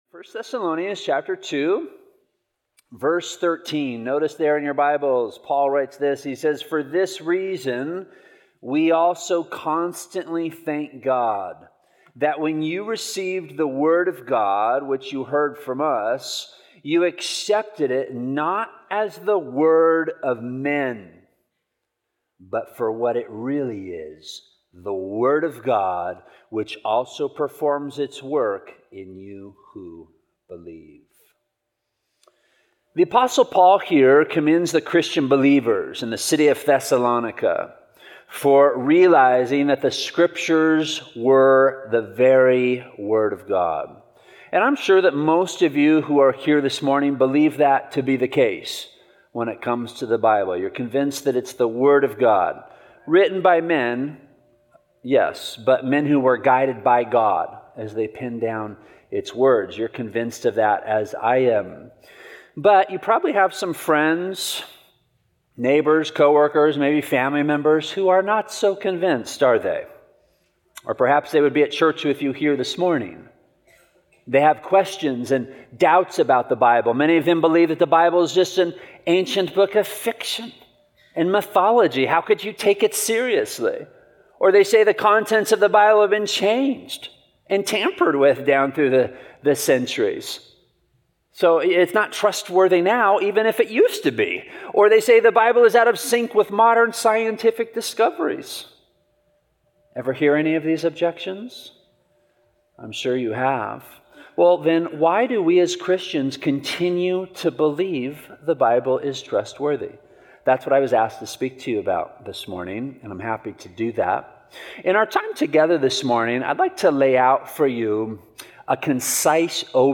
A verse-by-verse sermon through 1 Thessalonians 2:13